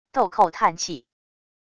豆蔻叹气wav音频